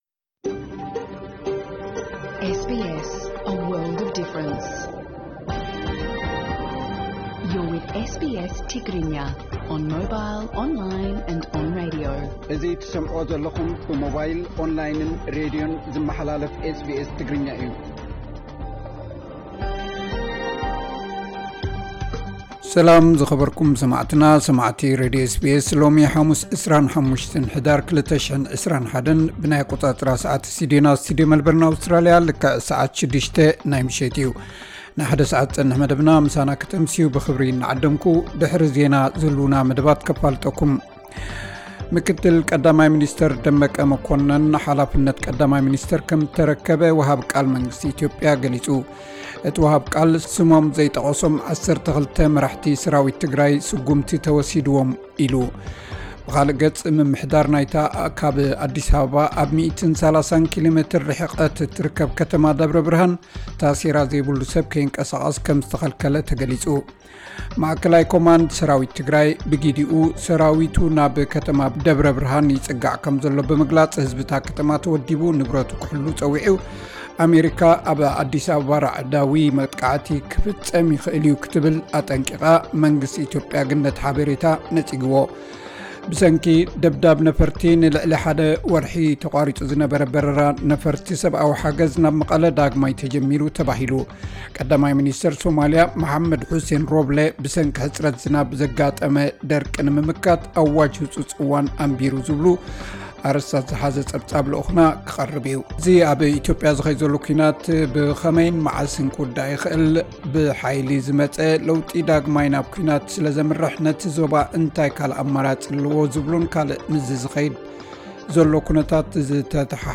ዕለታዊ ዜና SBS ትግርኛ (25 ሕዳር 2021)